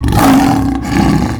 lion1.wav